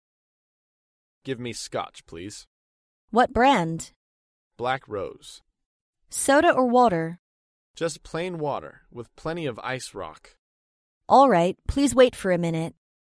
在线英语听力室高频英语口语对话 第463期:喝威士忌(1)的听力文件下载,《高频英语口语对话》栏目包含了日常生活中经常使用的英语情景对话，是学习英语口语，能够帮助英语爱好者在听英语对话的过程中，积累英语口语习语知识，提高英语听说水平，并通过栏目中的中英文字幕和音频MP3文件，提高英语语感。